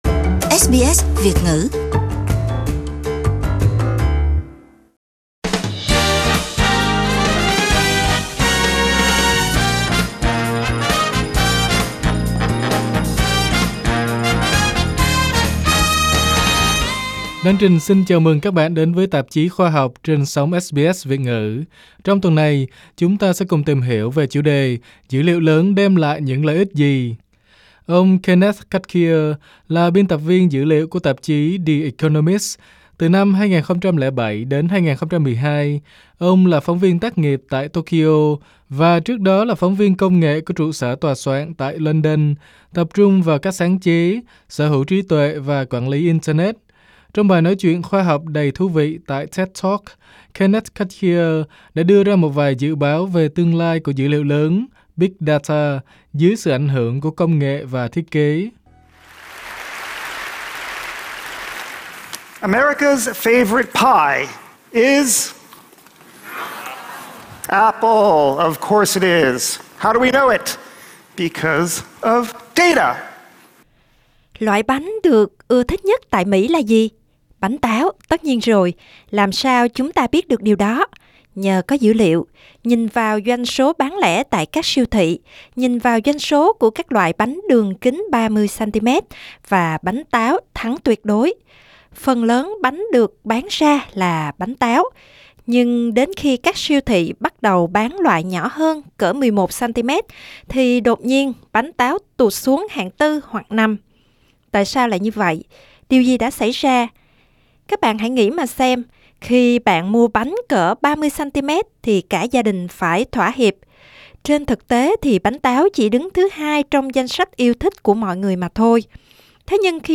Trong bài nói chuyện khoa học đầy thú vị tại TED, Kenneth Cukier đưa ra một vài dự báo về tương lai của Dữ liệu lớn (Big Data) dưới sự ảnh hưởng của công nghệ và thiết kế.